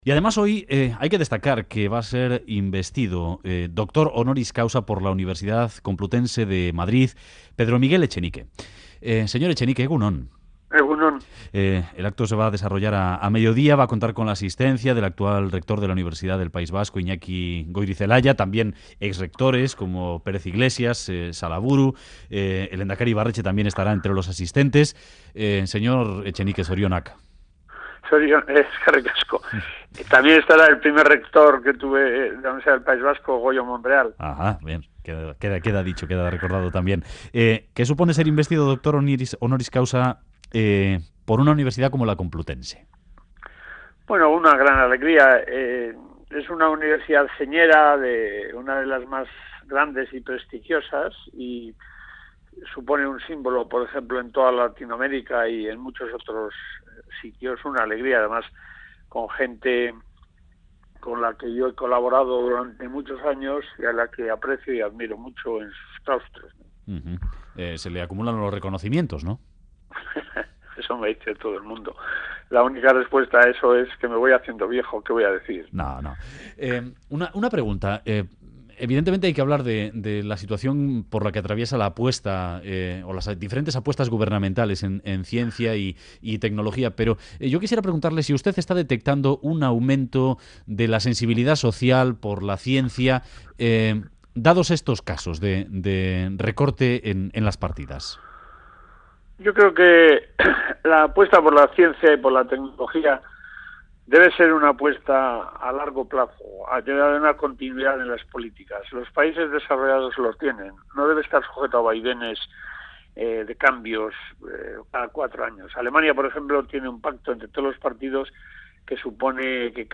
Radio Euskadi BOULEVARD Etxenike: 'La apuesta por la ciencia debe de ser a largo plazo' Última actualización: 07/11/2013 09:36 (UTC+1) En entrevista al Boulevard de Radio Euskadi, el físico y Premio Príncipe de Asturias, Pedro Miguel Etxenike, muestra su orgullo por ser investido Doctor Honoris Causa por la Universidad Complutense de Madrid. Reitera su apuesta por la educación, la ciencia y la tecnología, y subraya que tiene que ser a largo plazo para garantizar el futuro.